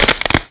barnacle_crunch1.ogg